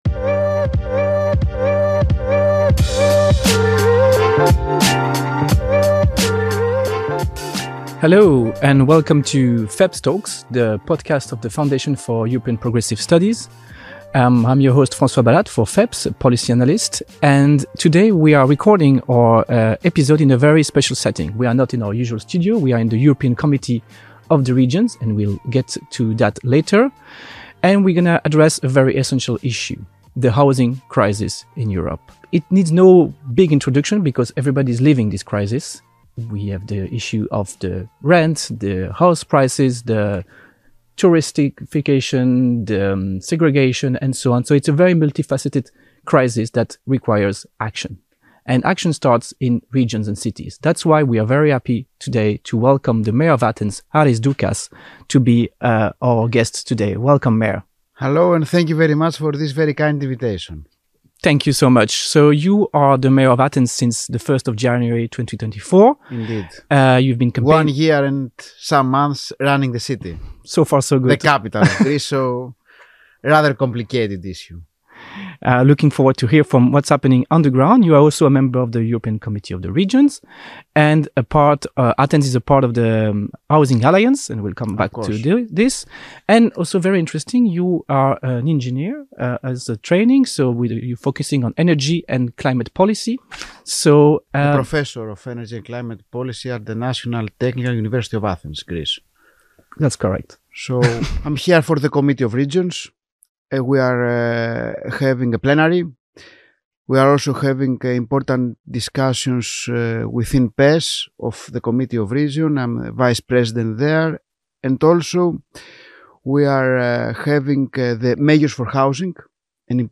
Tune in for a thought-provoking discussion that connects policy, strategy, and social values.